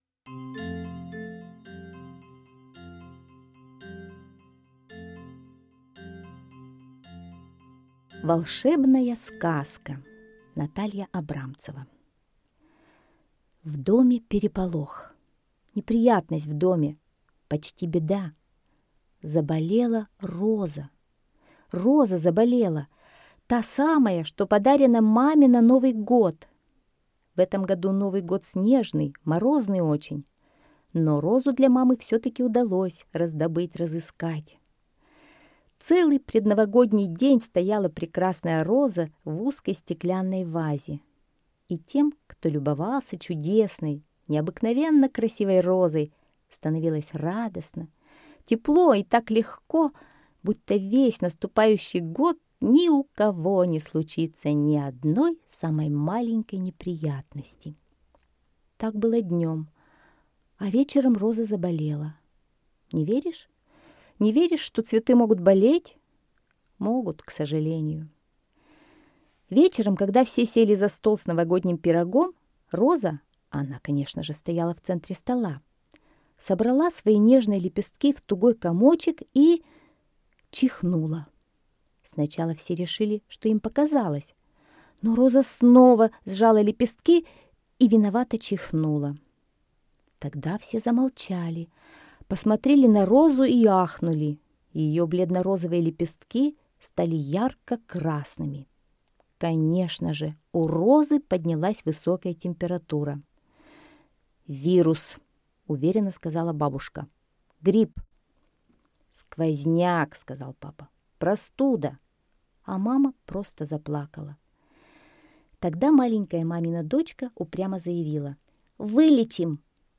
Слушайте Волшебная сказка - аудиосказка Абрамцевой Н. Сказка про то, как под Новый год заболела красивая роза, которую подарили маме.